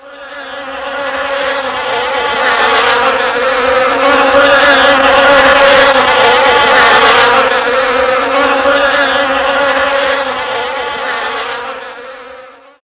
Bienen klingelton kostenlos
Kategorien: Tierstimmen